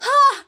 Play Hornet Hurt 2 - SoundBoardGuy
Play, download and share Hornet Hurt 2 original sound button!!!!
hollow-knight-hornet-voice-7.mp3